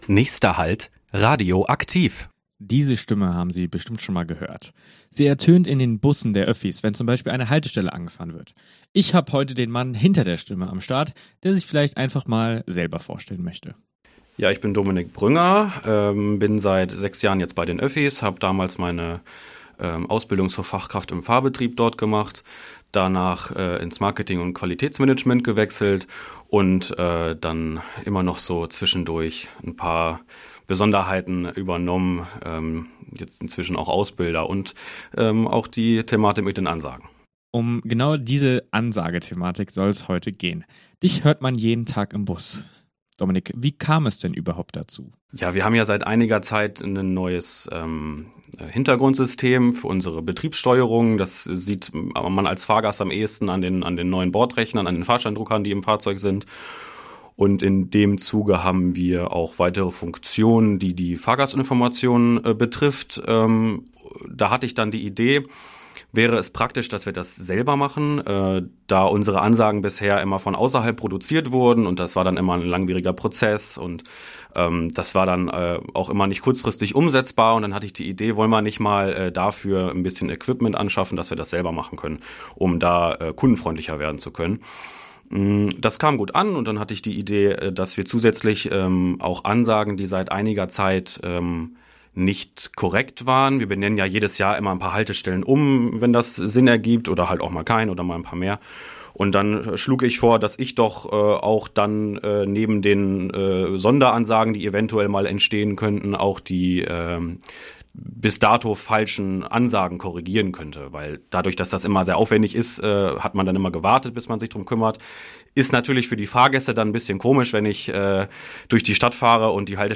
Komplettes Interview mit der Stimme im Bus